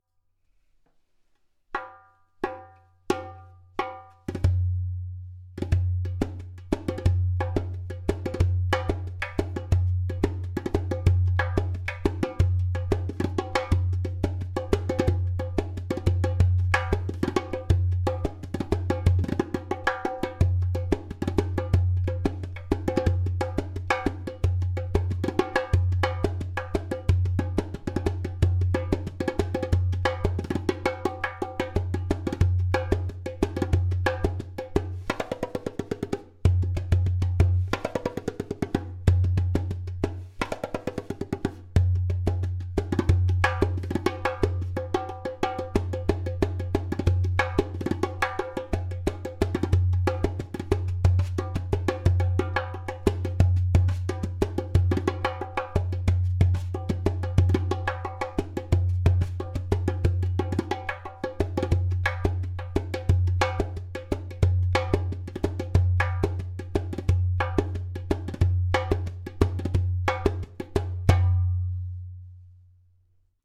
Premium Bronze 9.5 inch darbuka with goat Skin
90 bpm:
• Taks with harmonious overtones.
• Even tonality on around rim.
• Deep bass.
• Loud clay kik/click sound!